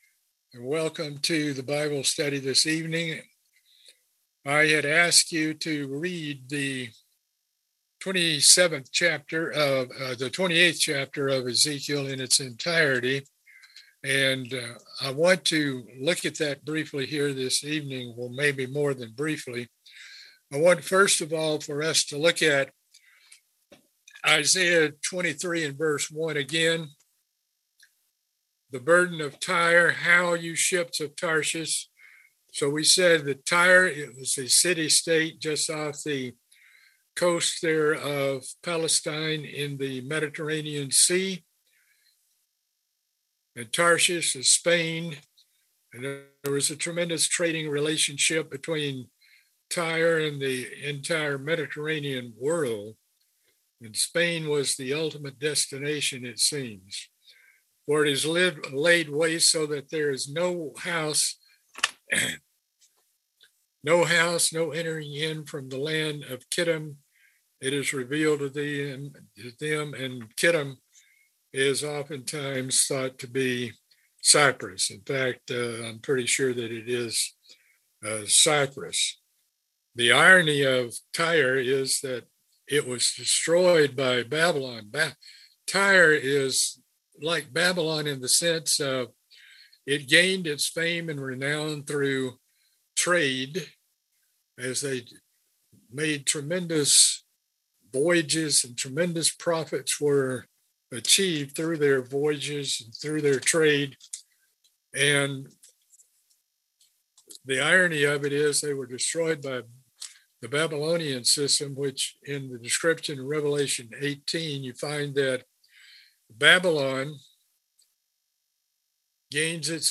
Book of Isaiah Bible Study - Part 18